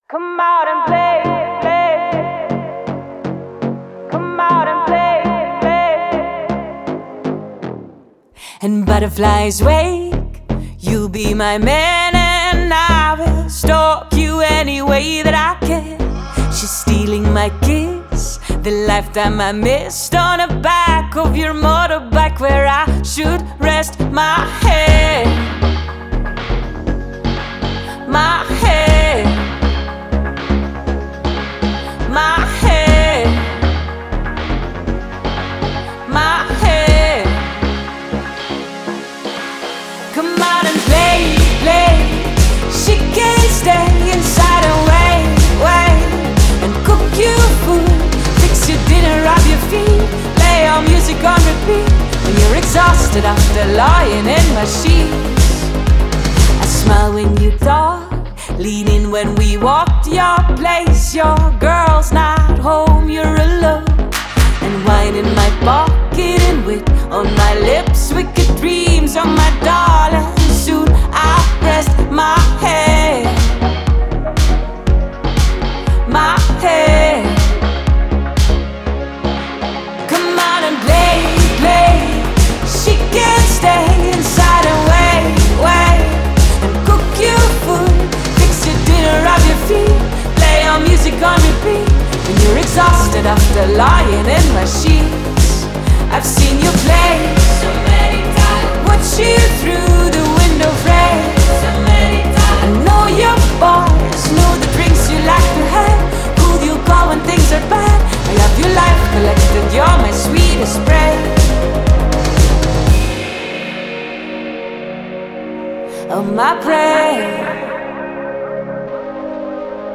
cheeky, controversial, and strikingly human!